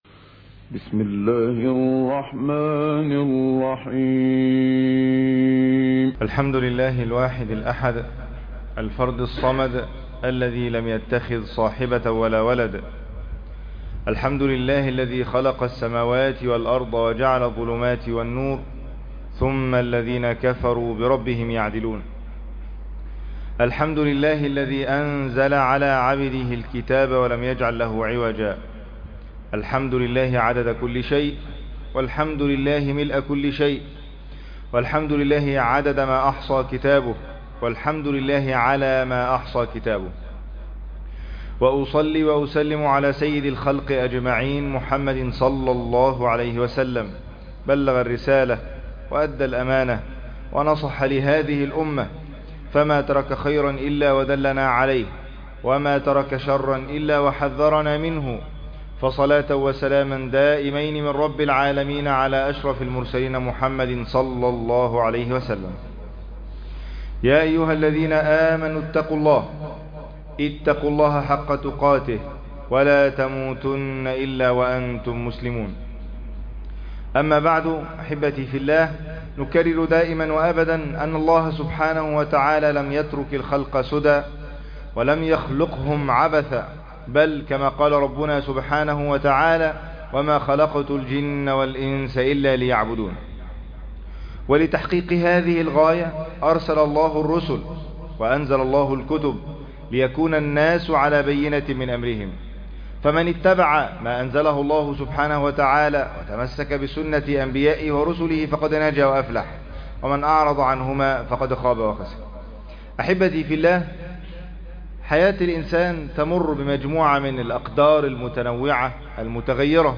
شهادة أولي العلم | خطبة جمعة